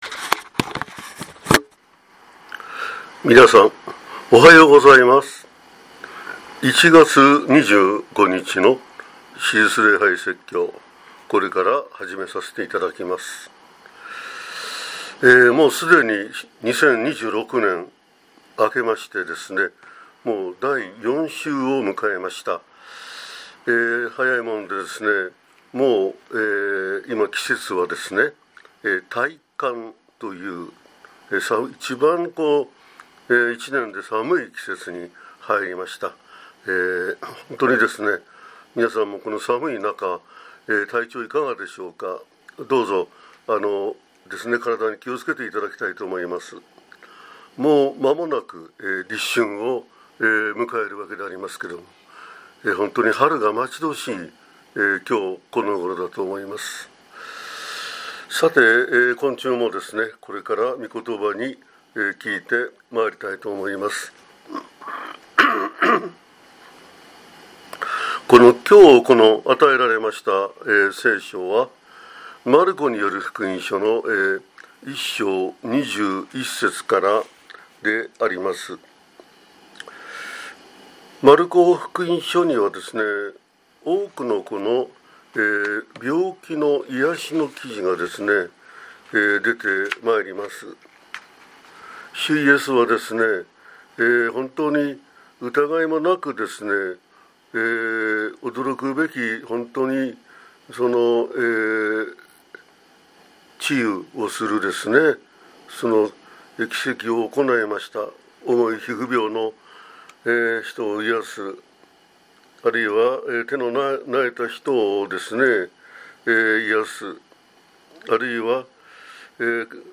説教 病を癒すイエス